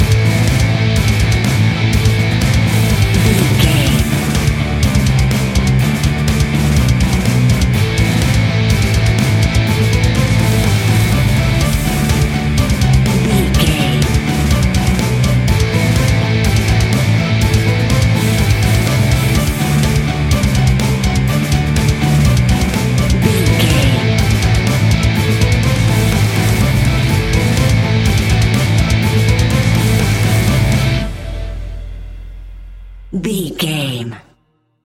Heavy Metal Action Music 30 Sec.
Epic / Action
Fast paced
Aeolian/Minor
Fast
hard rock
Heavy Metal Guitars
Metal Drums
Heavy Bass Guitars